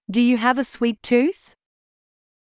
ﾄﾞｩ ﾕｳ ﾊﾌﾞ ｱ ｽｳｨｰﾄ ﾄｩｰｽ